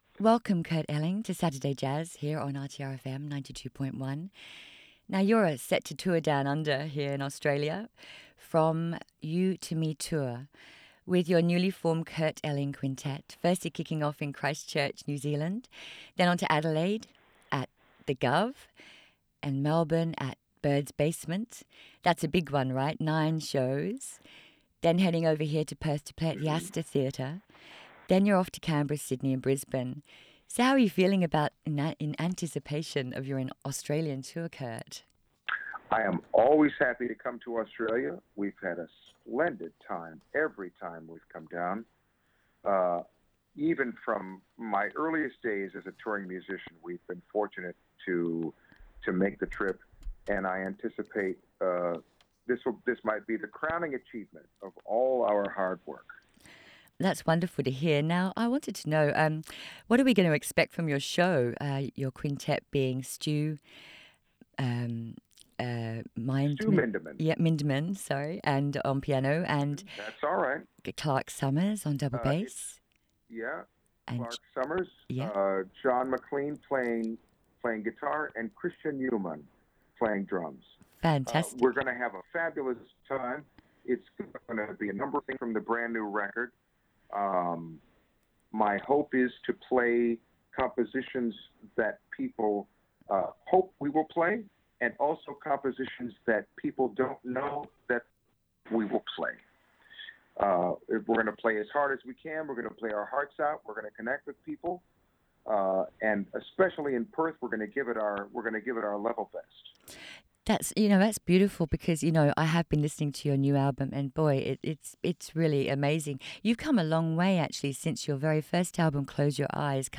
interview.wav